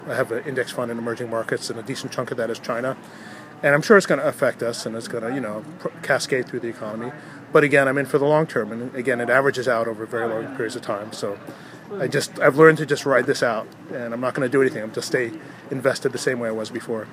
THIS MAN IN THE BOSTON AREA WHO HAS 15-PERCENT OF HIS STOCK PORTFOLIO IN EMERGING MARKETS AND CHINA SAYS HE’LL RIDE OUT THE DOWNTURN AS HE HAS BEFORE.